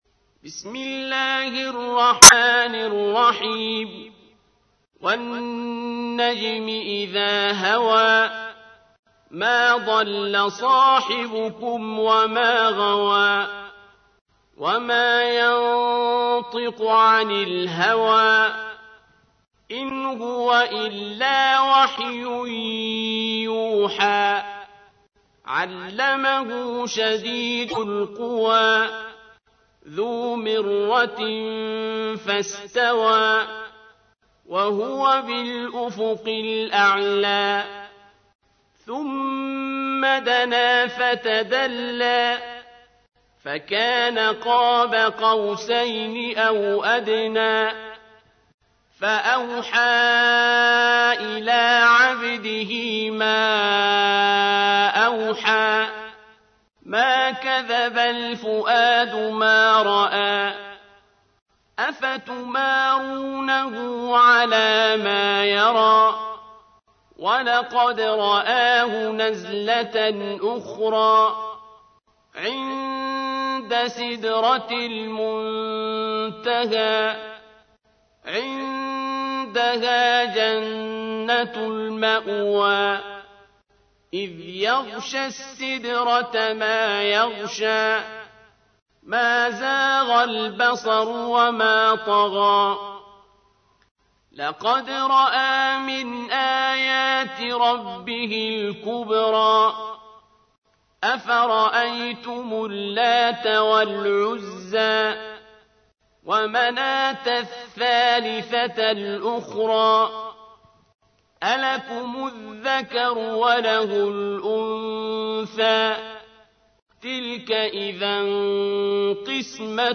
تحميل : 53. سورة النجم / القارئ عبد الباسط عبد الصمد / القرآن الكريم / موقع يا حسين